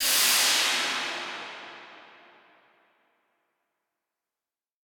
Index of /musicradar/shimmer-and-sparkle-samples/Filtered Noise Hits
SaS_NoiseFilterB-01.wav